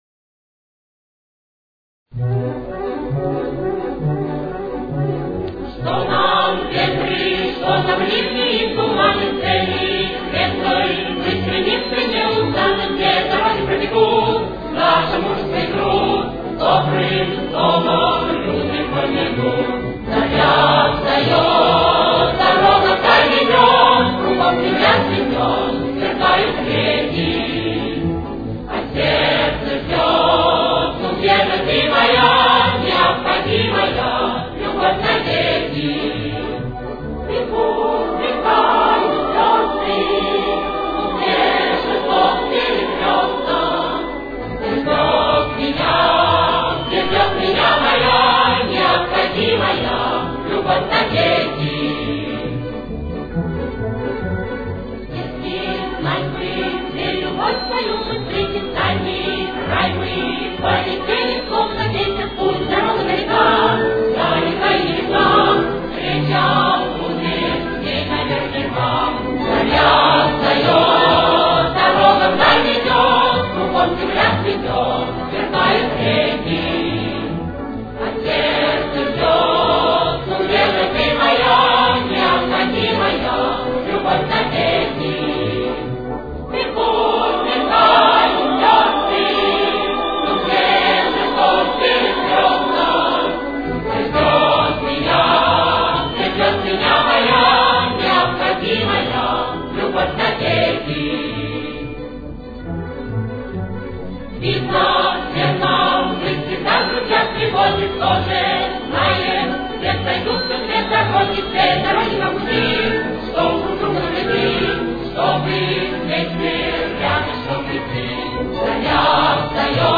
Си минор. Темп: 135.